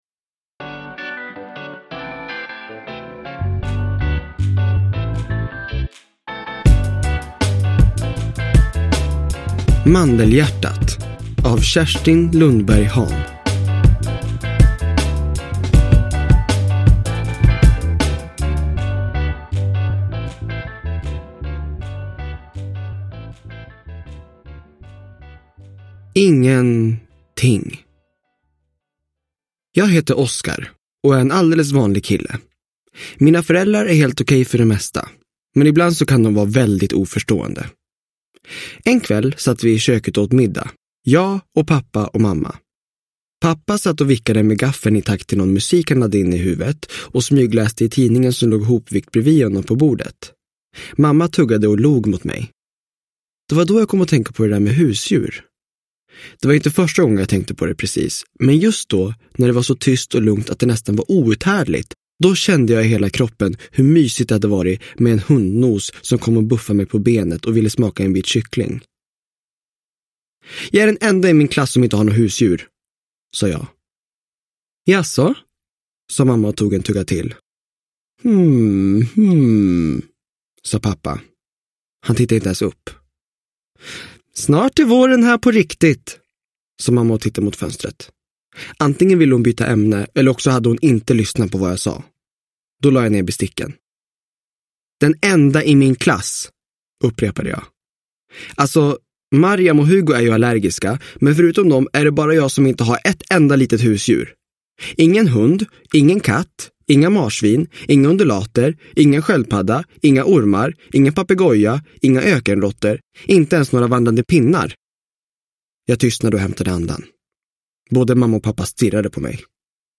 Mandelhjärtat – Ljudbok – Laddas ner
Uppläsare: William Spetz